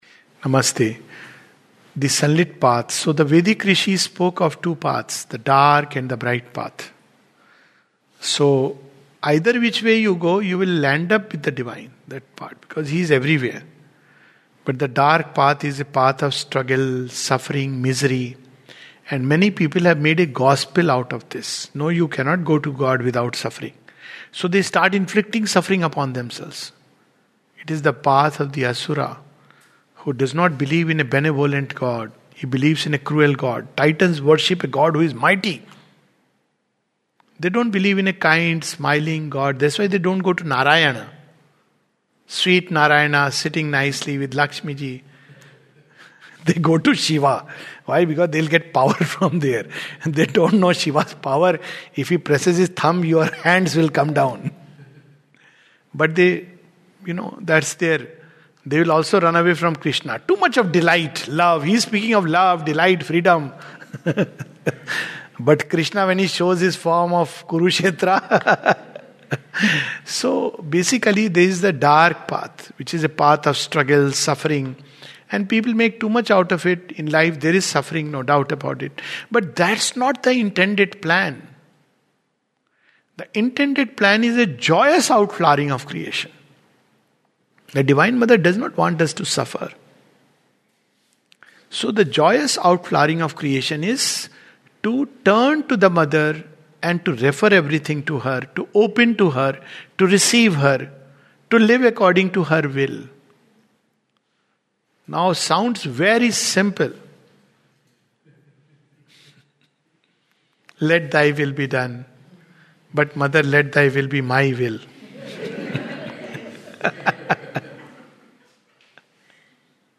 In this brief talk